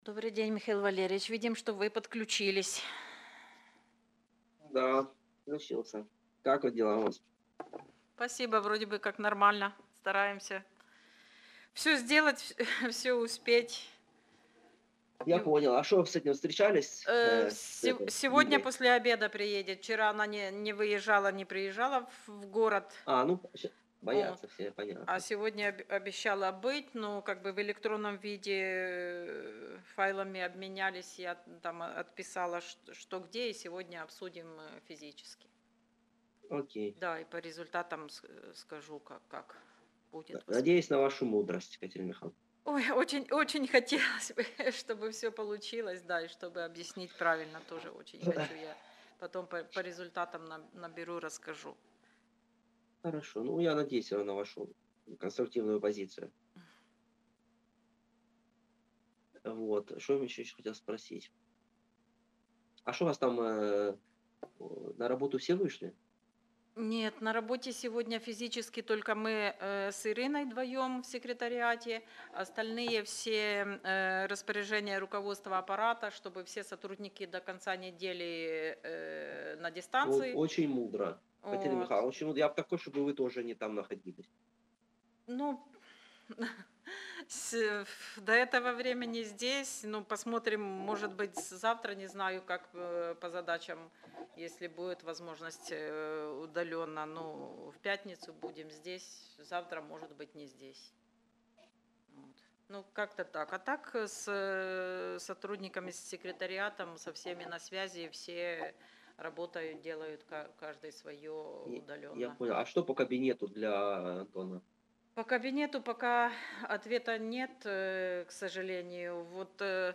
Аудіозапис засідання Комітету від 12.10.2022